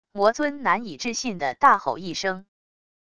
魔尊难以置信的大吼一声wav音频